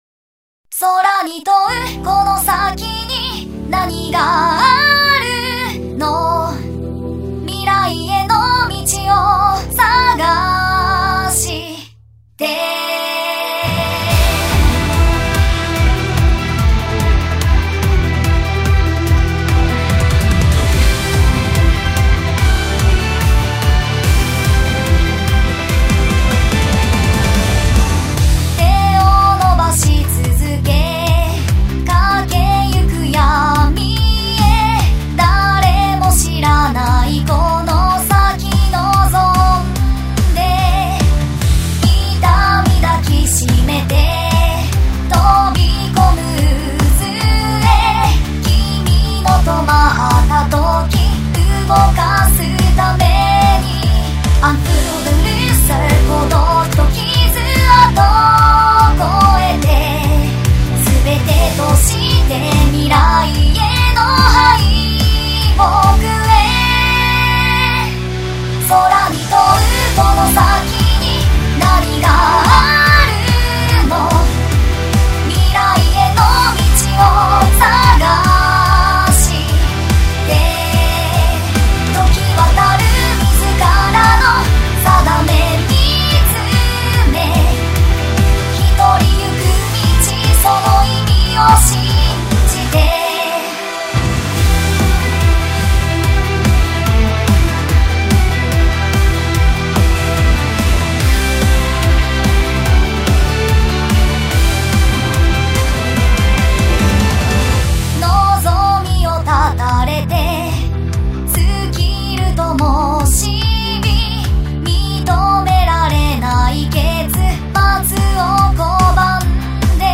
Inst(OGG) BGM(OGG